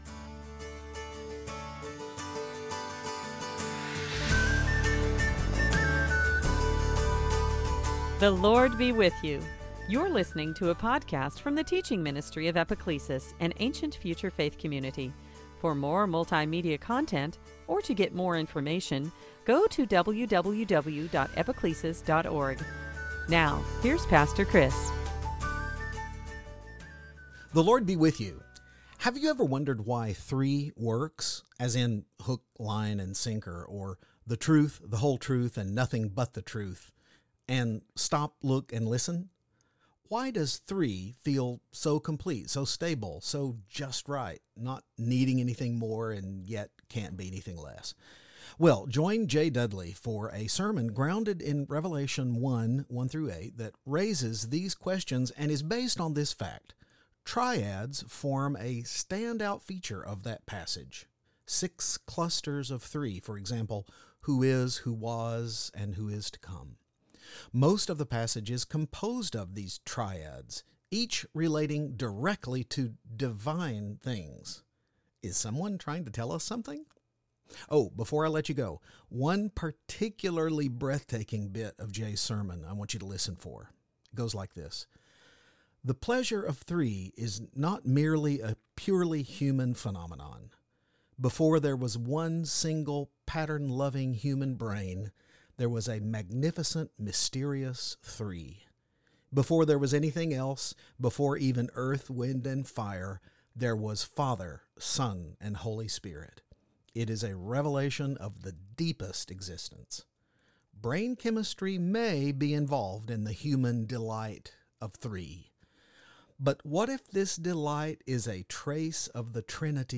Series: Sunday Teaching
Revelation 1:4-8 Service Type: Eastertide Have you ever wondered why “three” works ?